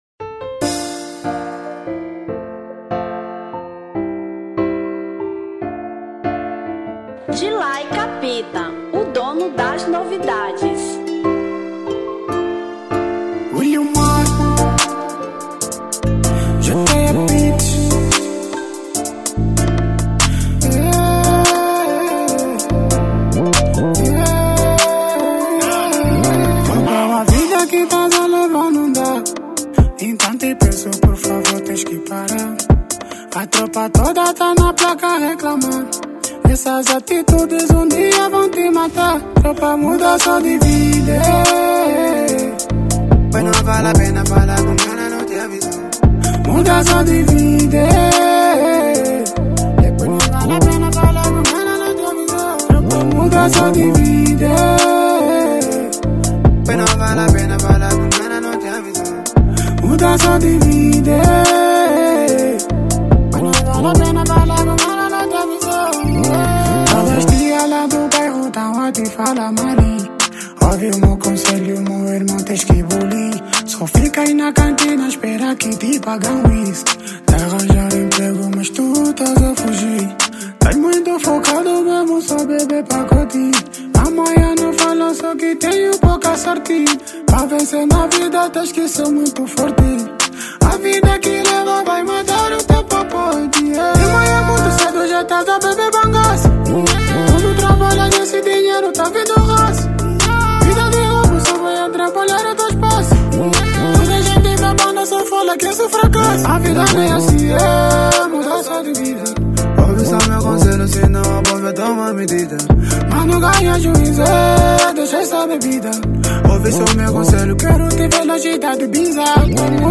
Drill 2024